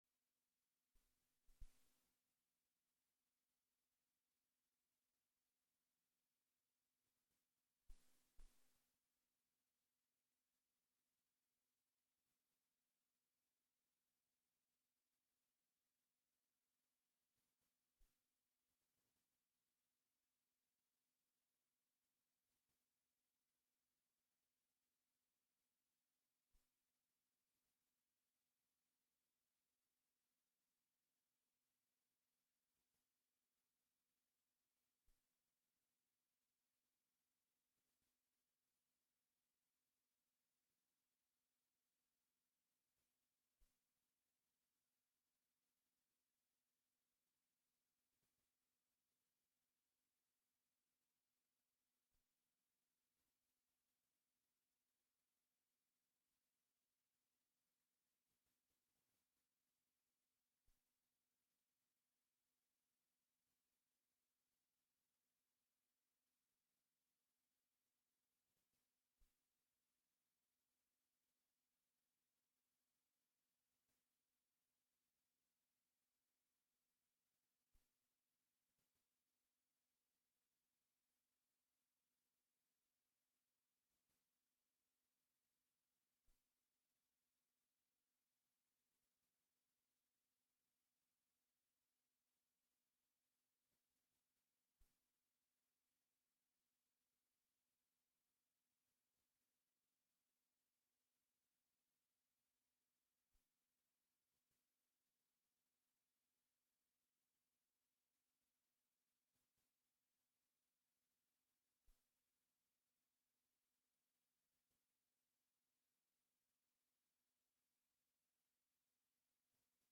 Event type Lecture